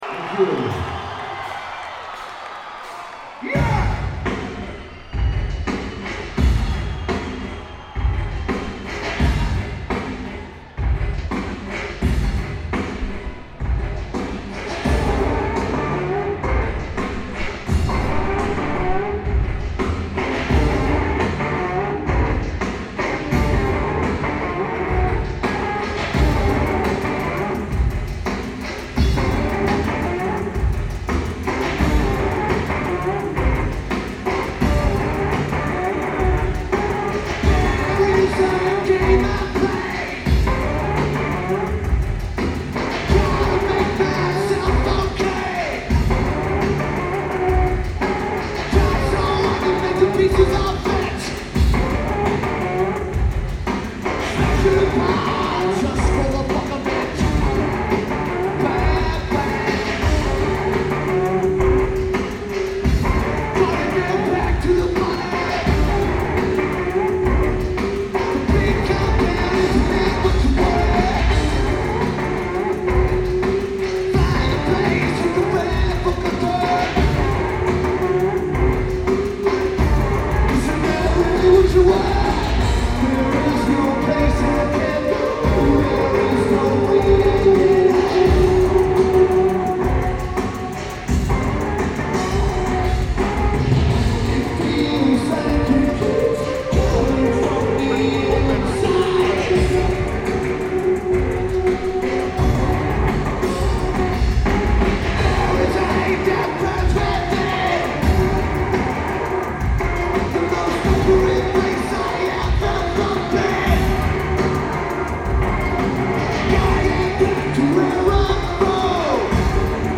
Starplex Amphitheater
Bass/Guitar
Drums
Lineage: Audio - AUD (CSC's + Sony TCD-D7)
The recording is ok.